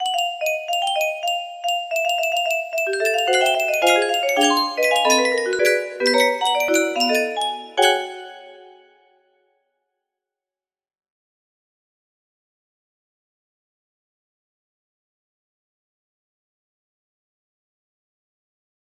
tech music box melody